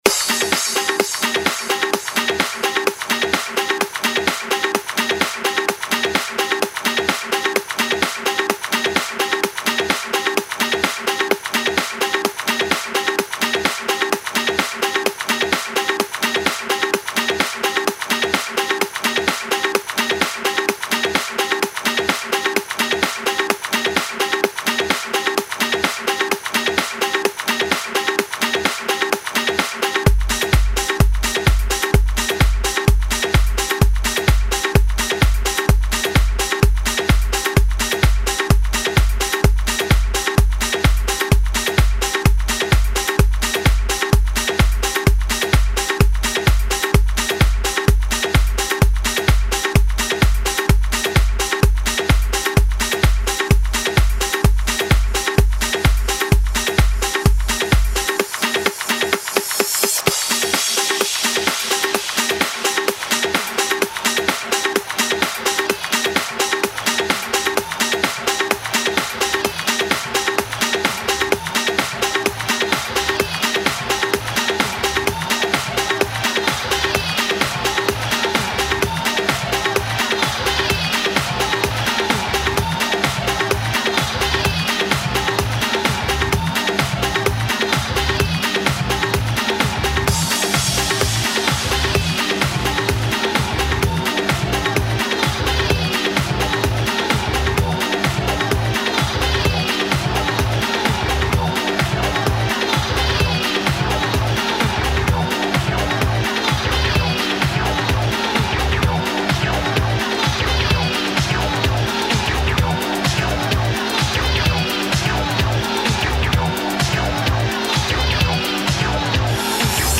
Хаус House Хаус музыка